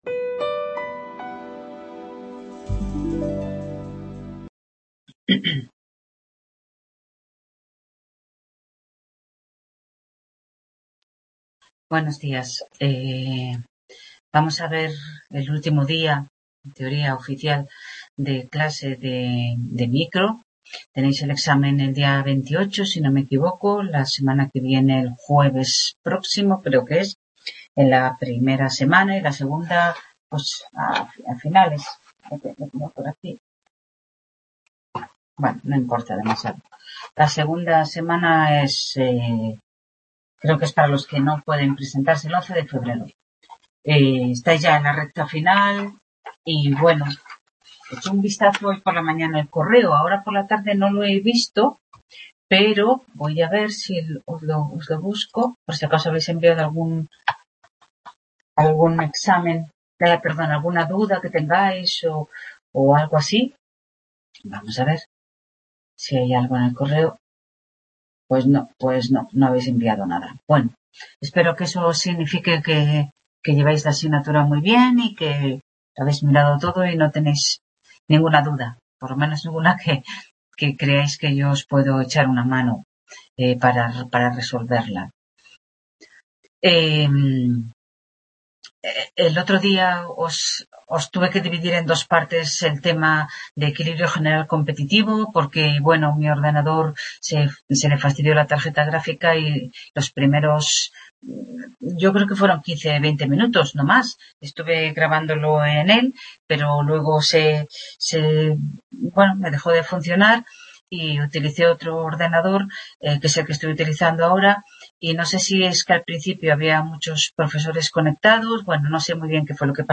Tutoría de Micoeconomía 2º ADE (tema oligopolio) 18/01… | Repositorio Digital